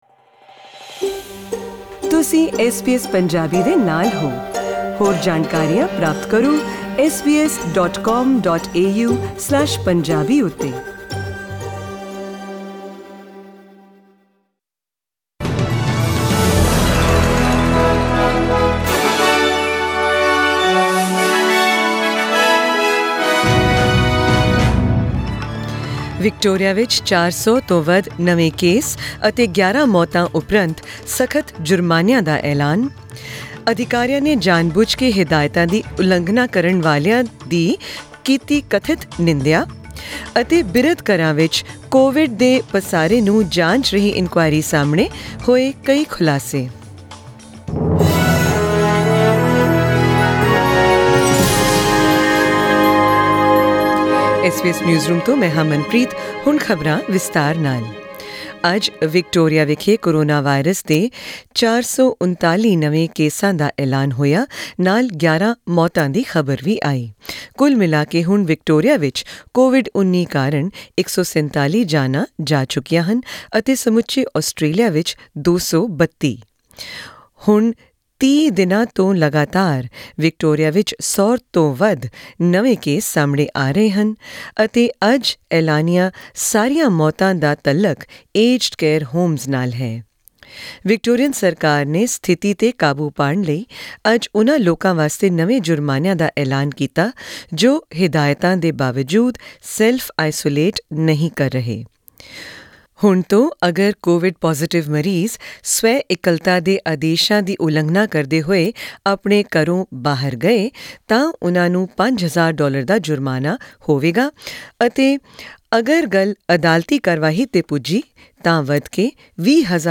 In tonight's news bulletin, hear about coronavirus updates from around the country, increased fines for restrictions in Victoria, and up to 170 seasonal workers are expected to begin work in farms in the Northern Territory under a new trial program.